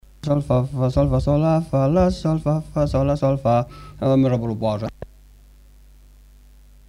Mélodie (notes chantées)
Aire culturelle : Savès
Genre : chant
Effectif : 1
Type de voix : voix d'homme
Production du son : chanté
Danse : rondeau